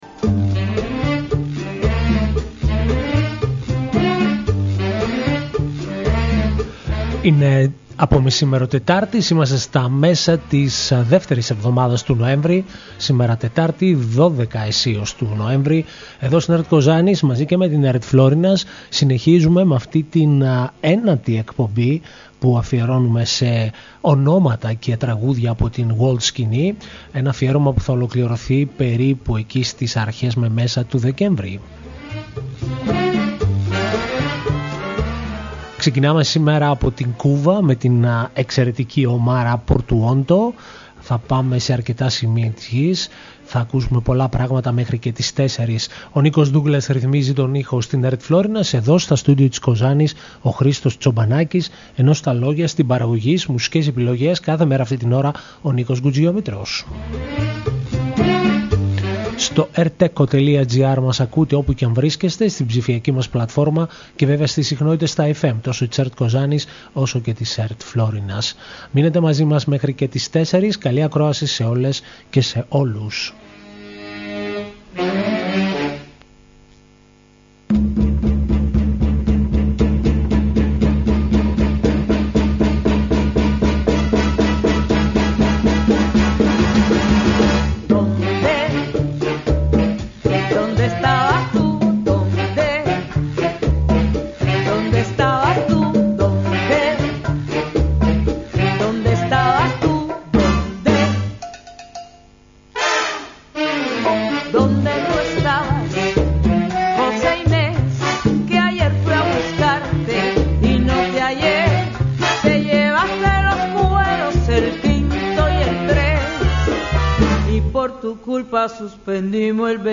παρουσιάζει σήμερα τραγούδια